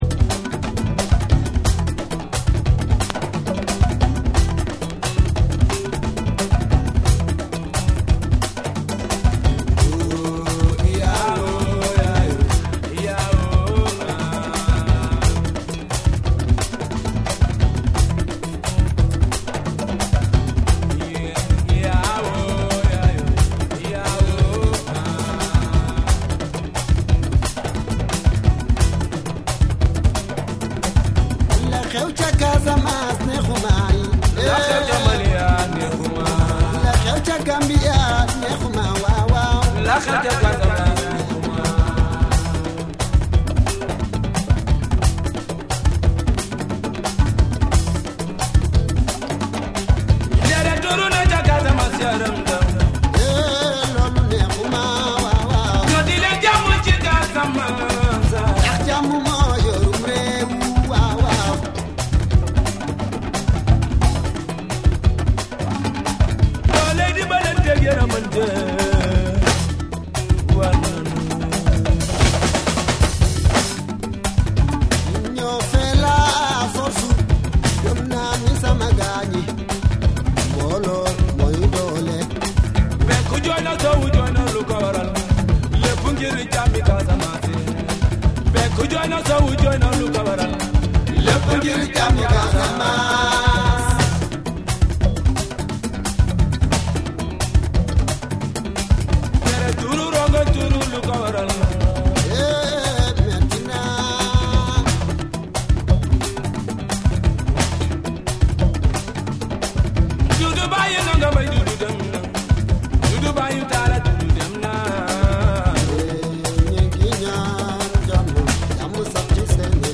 エネルギッシュなパーカッションと深みのあるベースが生み出すグルーヴが圧倒！
WORLD